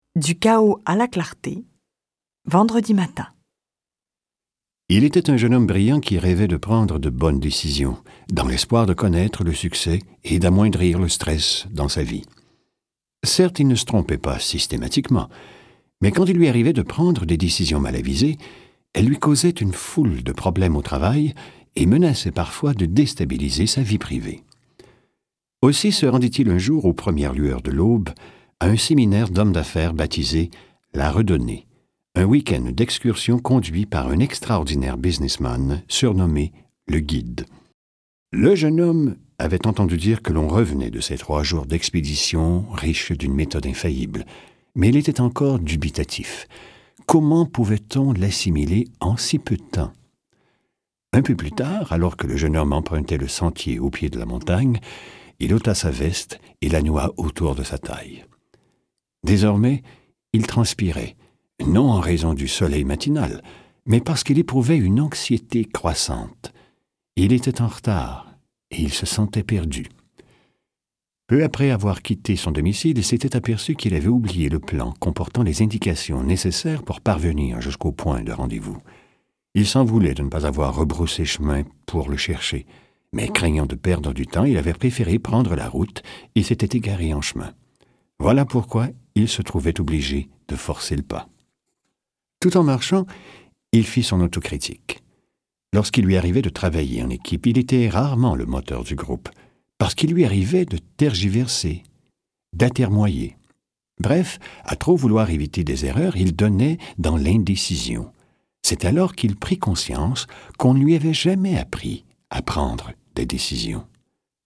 Lire un extrait - Oui ou non de Johnson Spencer
Lu par Spencer Johnson Durée : 01h56min 14 , 40 € Ce livre est accessible aux handicaps Voir les informations d'accessibilité